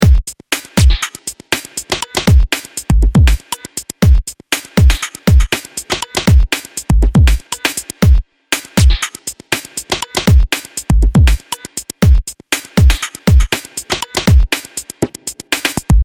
Má skladba má 120. a v první stopě mám bicí doprovod v tomto tempu.
120BPM.mp3